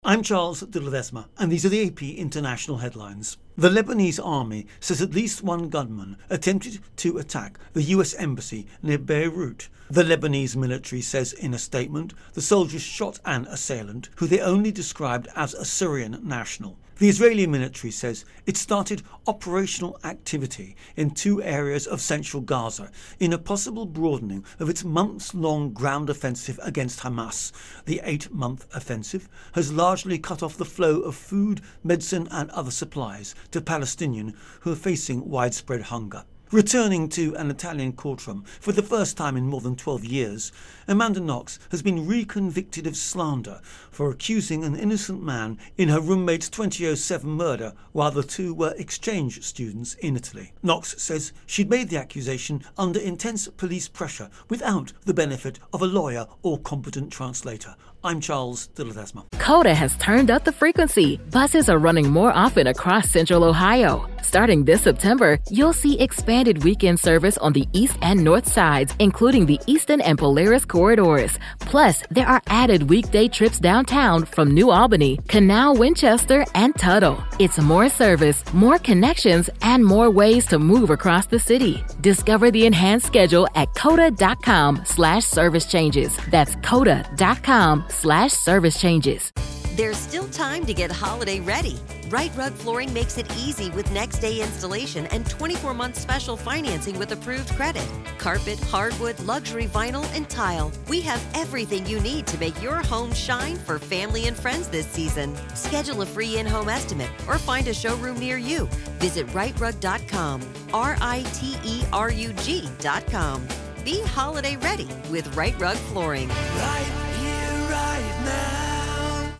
The latest international headlines